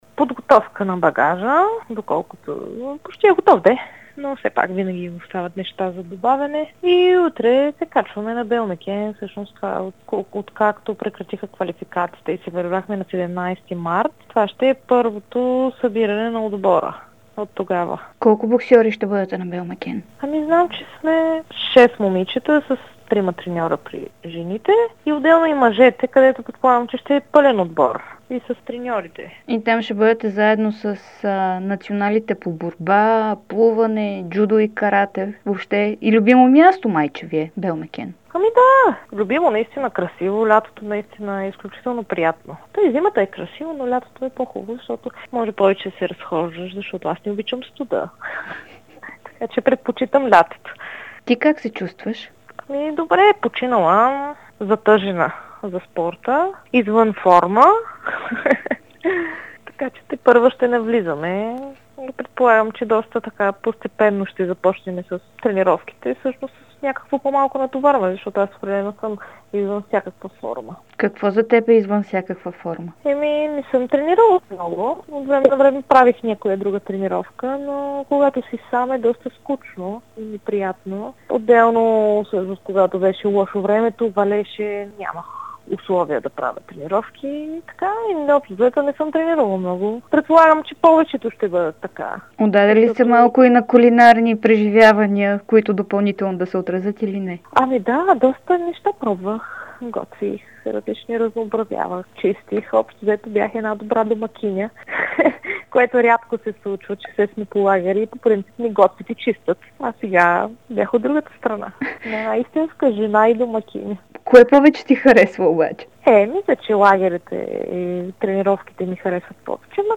Световната и Европейска шампионка по бокс Станимира Петрова даде специално интервю за Дарик радио и dsport, в което обяви, че се е затъжила за спорта и чака с нетърпение да се качи на лагер на Белмекен. Тя бе категорична, че не се страхува от коронавируса и допълни, че е използвала извънредното положение, за да си почине и да обърне внимание на близките си.